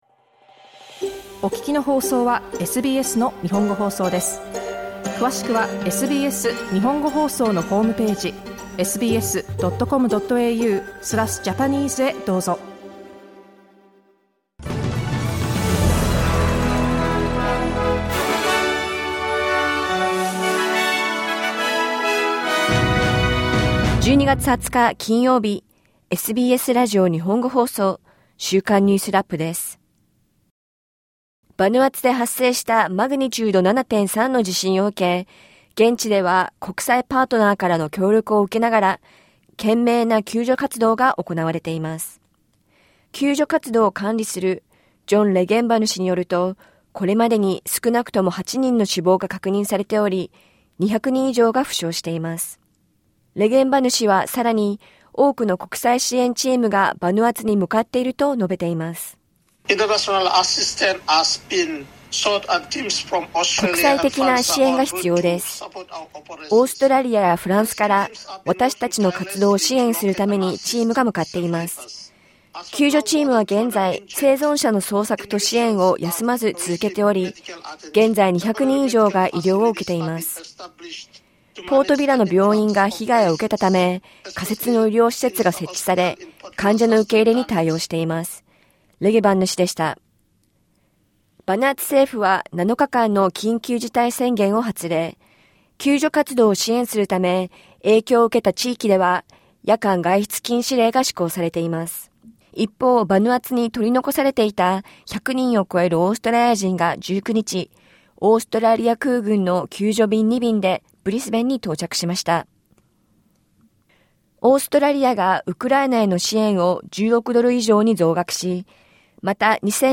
SBS日本語放送週間ニュースラップ 12月20日金曜日
バヌアツで発生したマグニチュード7.3の地震を受け、現地に取り残されていたオーストラリア人が帰国しました。1週間を振り返る週間ニュースラップです。